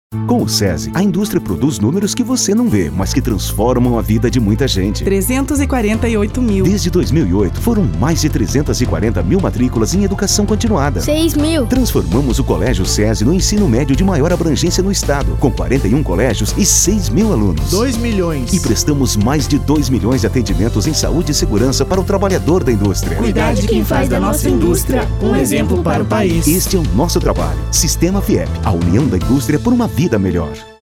Confira o spot da Campanha Institucional do SESI.